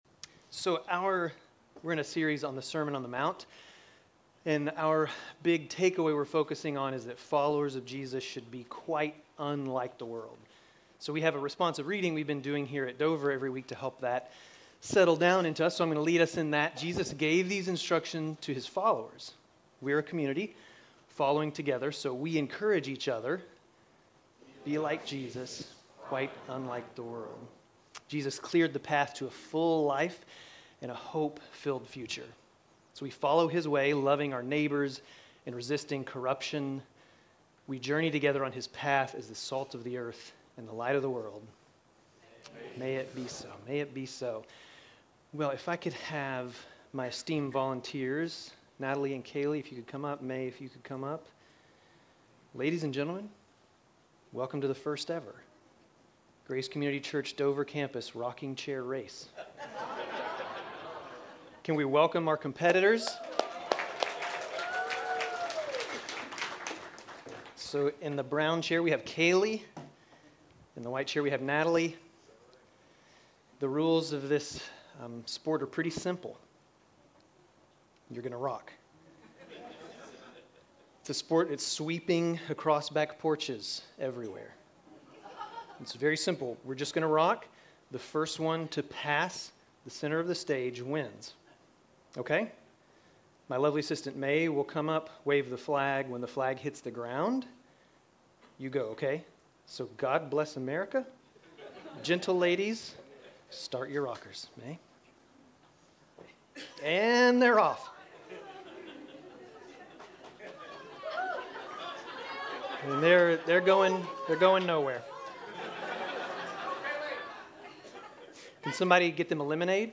Grace Community Church Dover Campus Sermons 3_16 Dover Campus Mar 16 2025 | 00:28:44 1x 00:00 / 00:28:44 Subscribe Share RSS Feed Share Link Embed